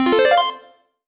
collect_item_chime_01.wav